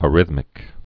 (ə-rĭthmĭk)